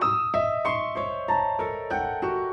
Index of /musicradar/gangster-sting-samples/95bpm Loops
GS_Piano_95-E2.wav